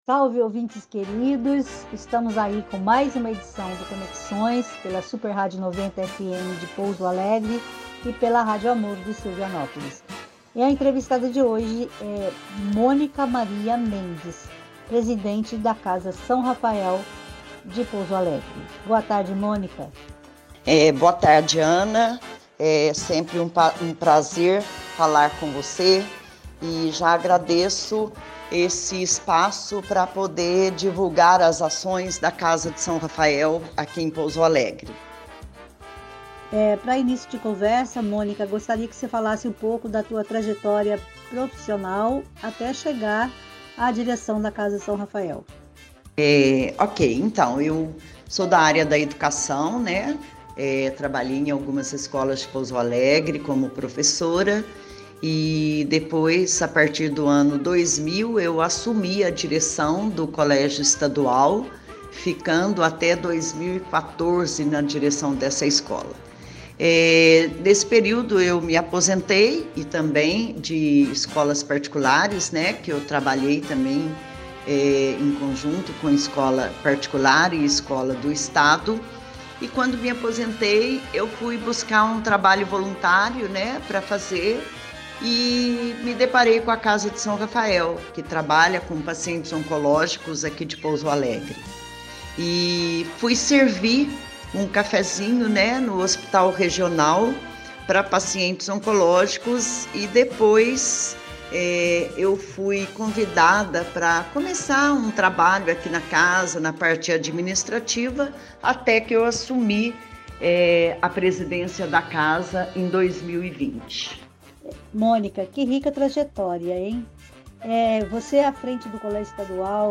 Com ênfase para entrevistas na área cultural, o Programa vai ao ar aos domingos, às 13h30.